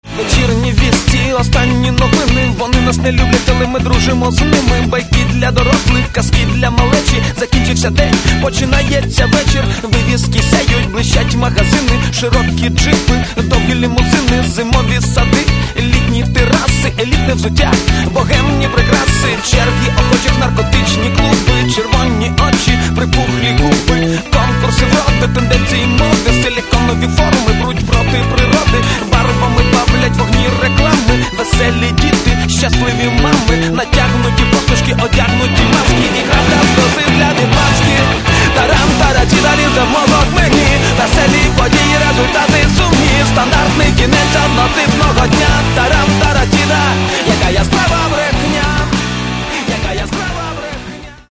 Каталог -> Хип-хоп